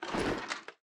PixelPerfectionCE/assets/minecraft/sounds/item/armor/equip_gold6.ogg at bbd1d0b0bb63cc90fbf0aa243f1a45be154b59b4
equip_gold6.ogg